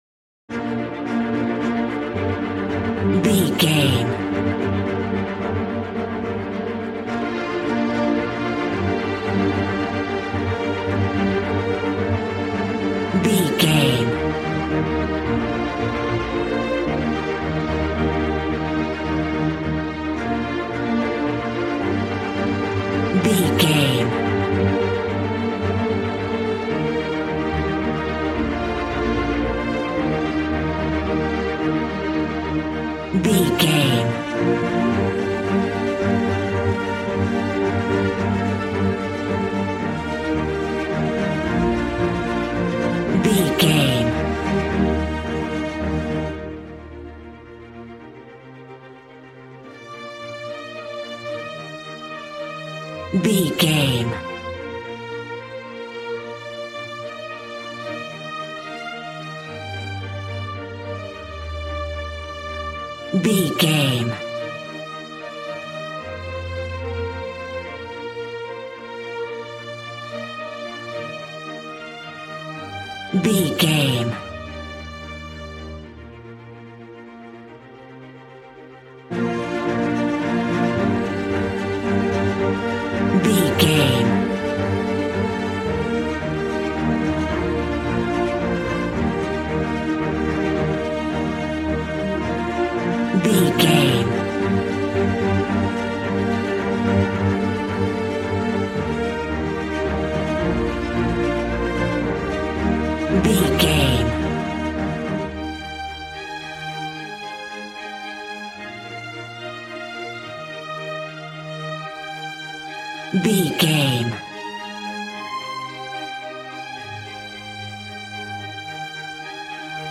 Ionian/Major
B♭
Fast
regal
strings
brass